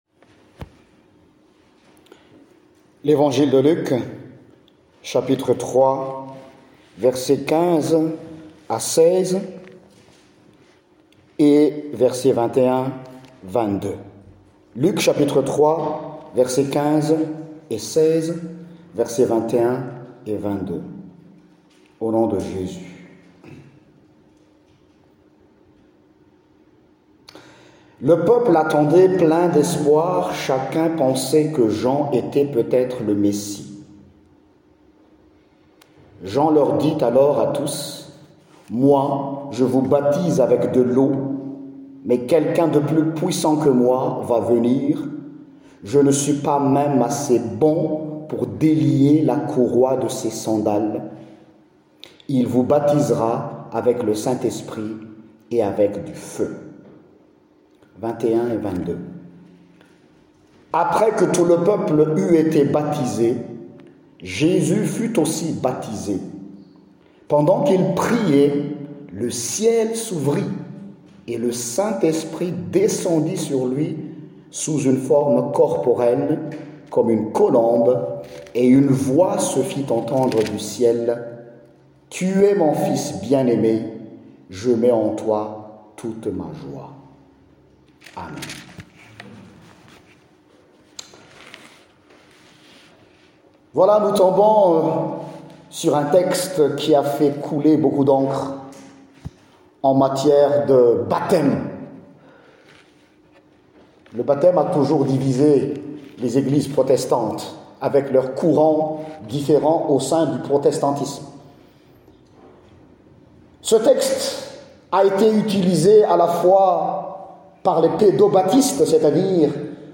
Le Dieu des nuls (Prédication 09/01)